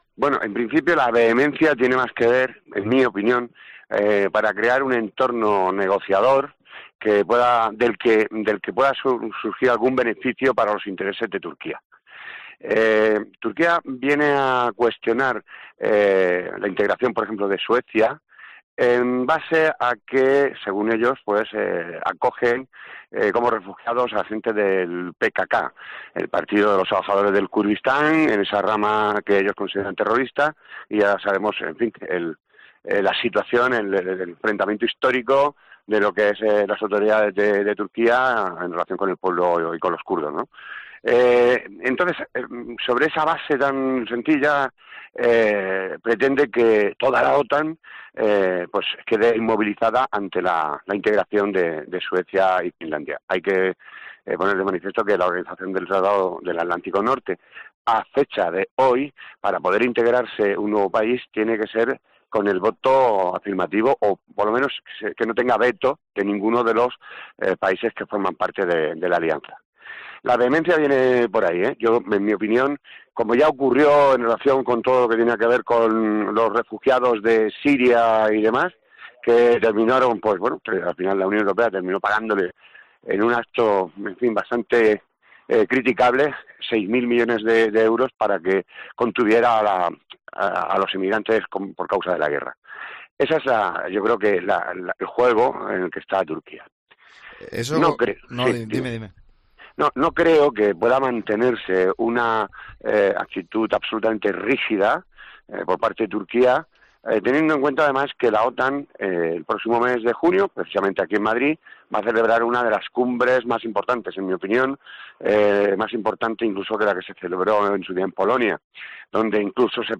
experto en geopolítica, explica en COPE los motivos detrás de la contundencia de Erdogan a la hora de negarse a negociar con ambos países...